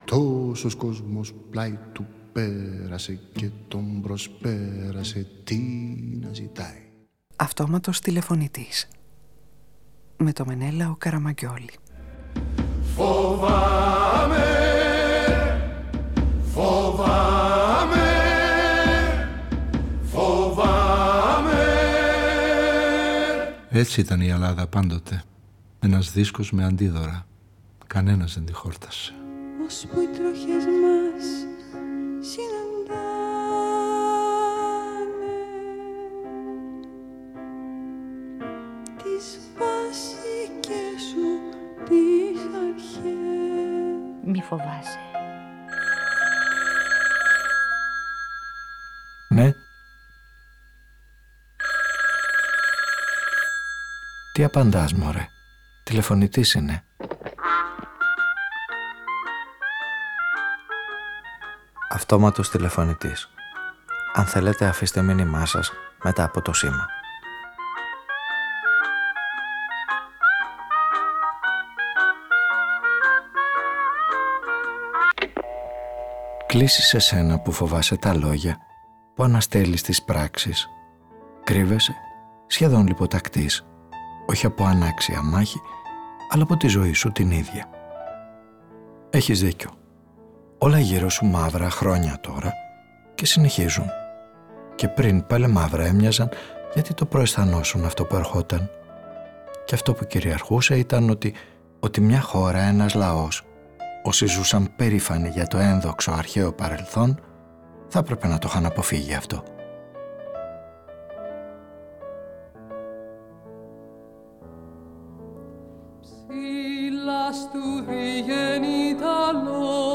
Υπάρχει ελληνικός τρόπος διαχείρισης κρίσεων; Ο ήρωας της σημερινής ραδιοφωνικής ταινίας ψάχνει να βρει με ποιούς τρόπους αυτό που -συχνά- μοιάζει λάθος και αποδιοργανώνει μπορεί να μεταβληθεί σε αποτελεσματική μέθοδο επανεκκίνησης, ειδικά όταν κανείς νιώθει αποκλεισμένος κι απομονωμένος. Πως ξαναρχίζει κανείς από την αρχή και κερδίζει όσα έχασε; Μαζί με οδηγίες γραμμένες απο τον Παπαδιαμάντη επαναπροσδιορίζει το νόημα μιας επετείου που φέτος θα συμβεί αόρατη.